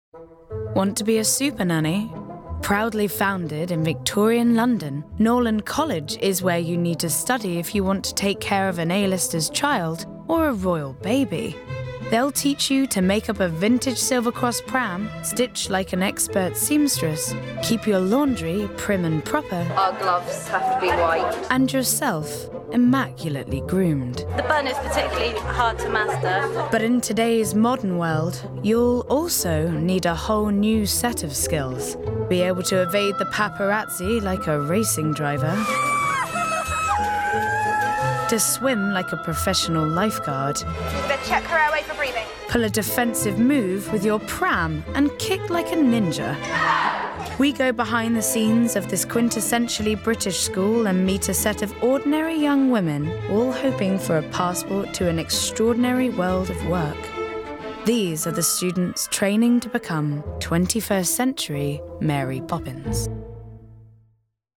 20/30's London/Neutral,
Contemporary/Natural/Earthy
• Documentary